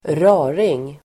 Uttal: [²r'a:ring]